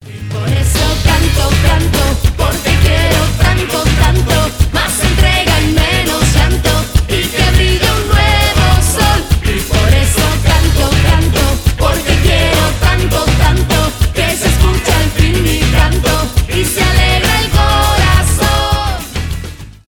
танцевальные
зажигательные